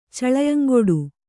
♪ caḷayaŋgoḍu